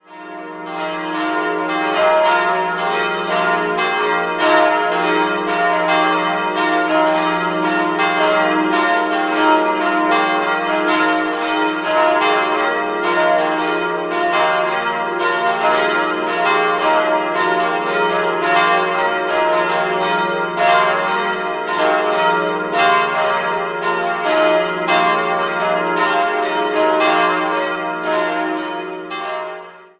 4-stimmiges Geläut: es'-f'-g'-b' Die Glocken 1, 3 und 4 wurden 1961 von der Gießerei Bachert in Karlsruhe gegossen.